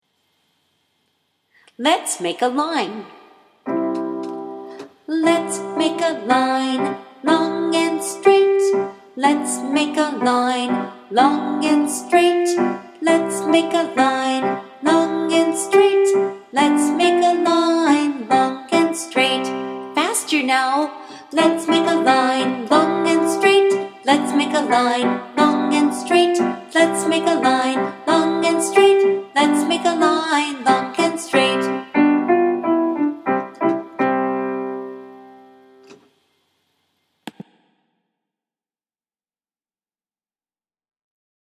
(melody:  Skip To My Lou)